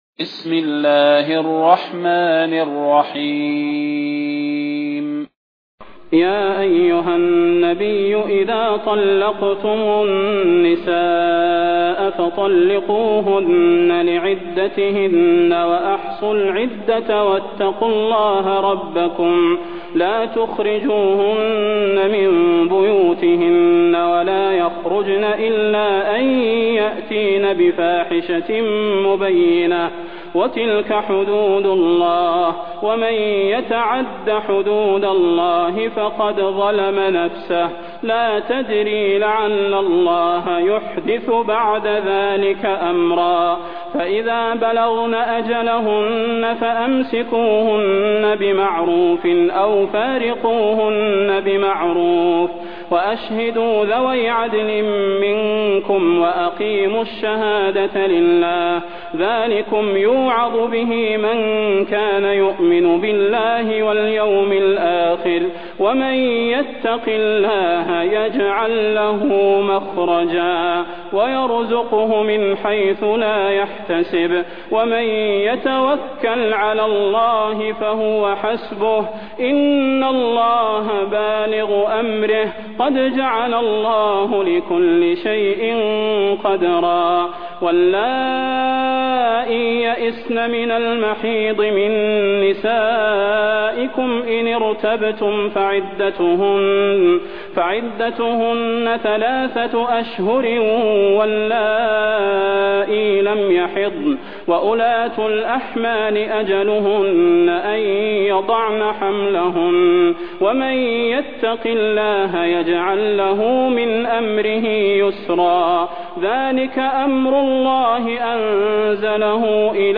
المكان: المسجد النبوي الشيخ: فضيلة الشيخ د. صلاح بن محمد البدير فضيلة الشيخ د. صلاح بن محمد البدير الطلاق